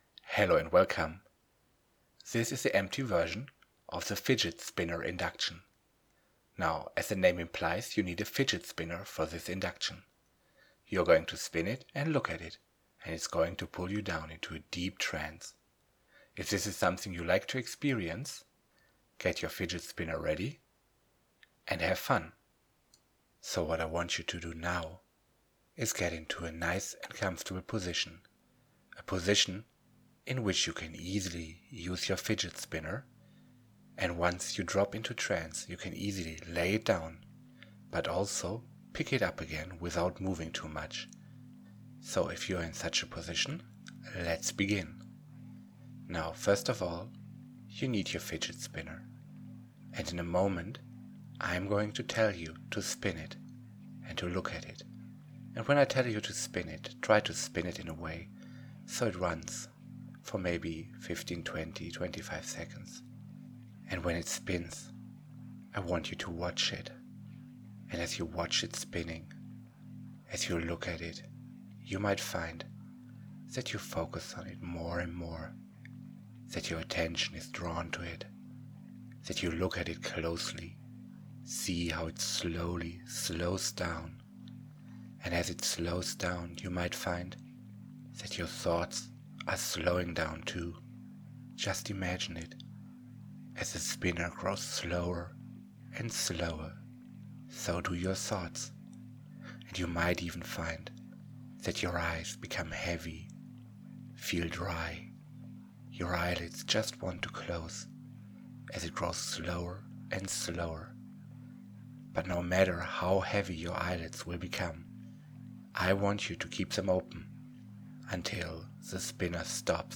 This is just the empty induction with an awakener at the end.